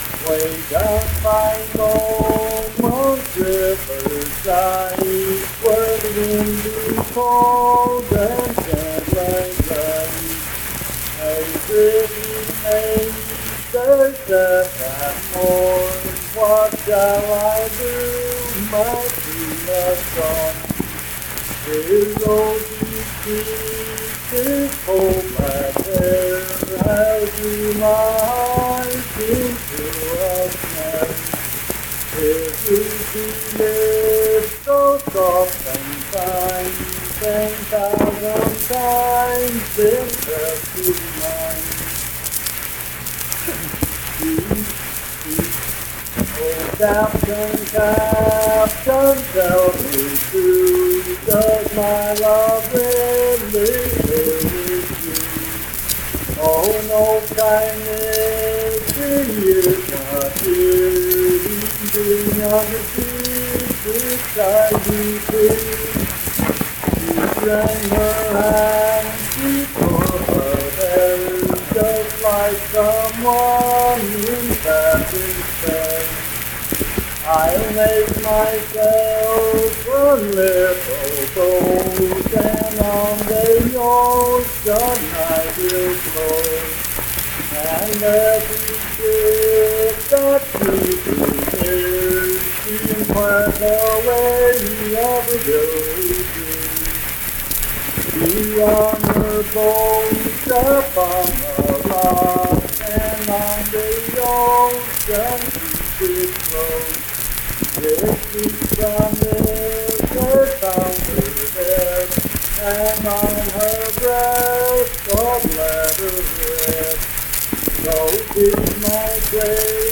Unaccompanied vocal music performance
Voice (sung)